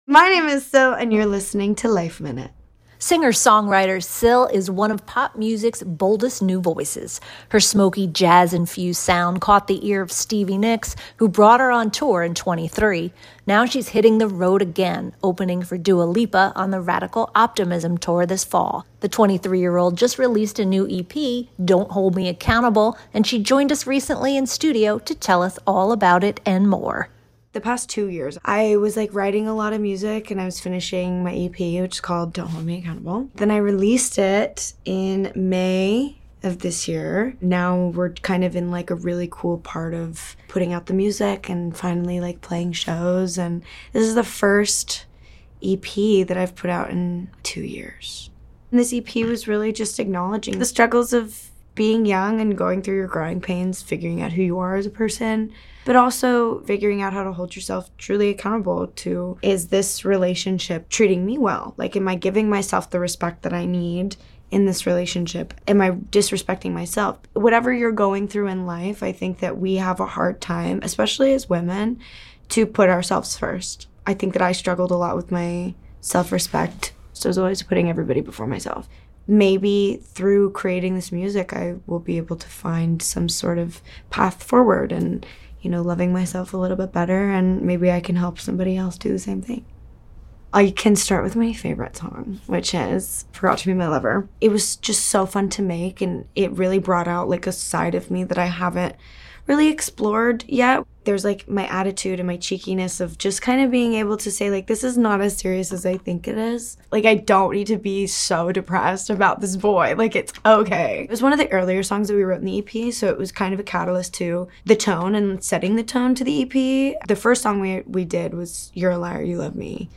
She joined us in studio to tell us all about it.